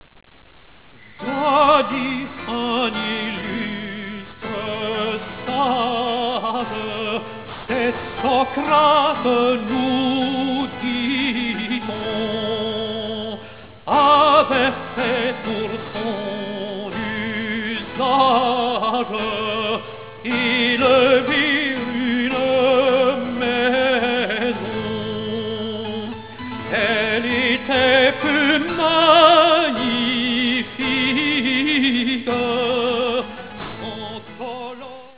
ténor
clavecin